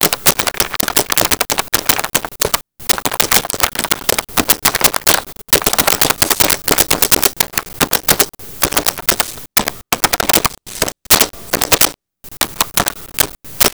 Computer Laptop Type
Computer Laptop Type.wav